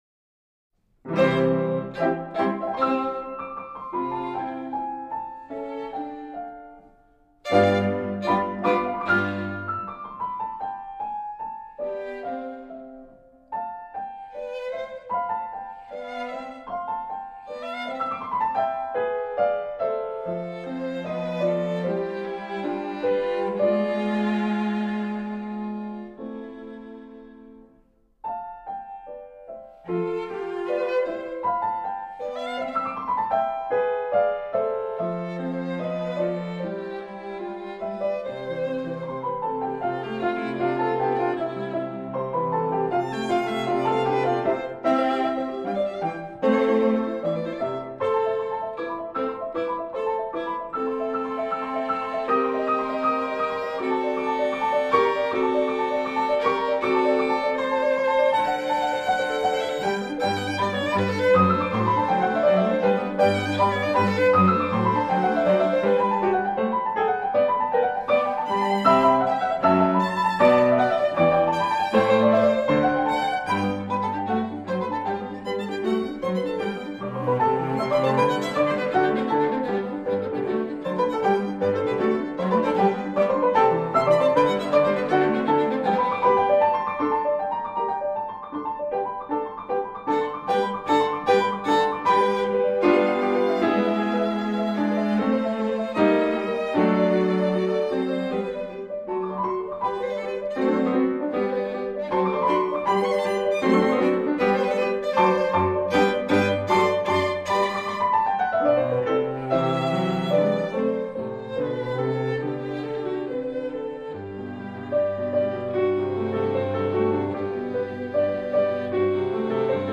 скрипка
виолончель
фортепиано